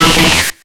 Cri d'Élekid dans Pokémon X et Y.